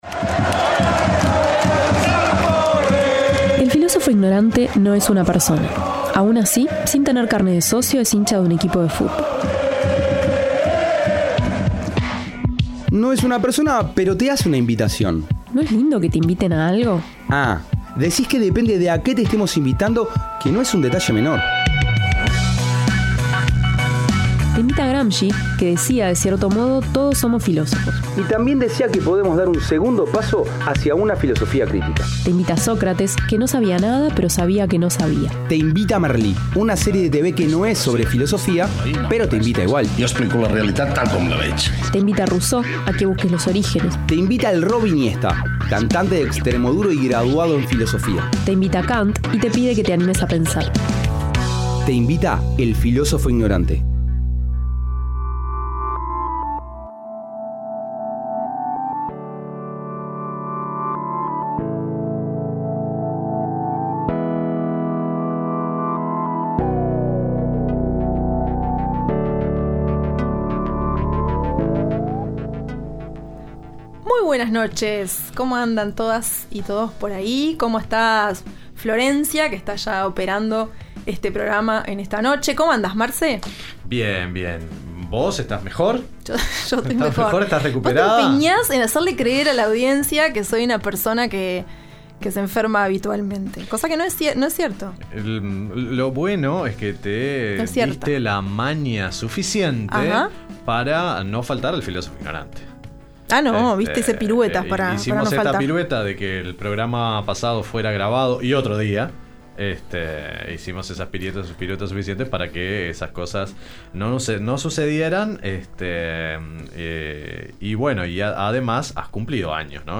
Además de citar autores que permiten desarrollar más los conceptos que se exponen, compartimos música temática elegida para la ocasión.